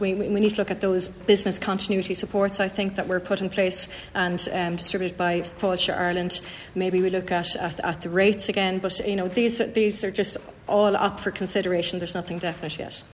Minister Catherine Martin says several options are being considered: